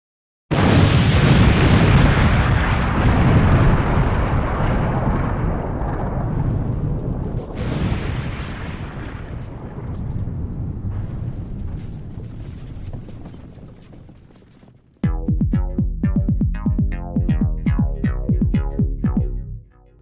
Sound Effects - Explosion 01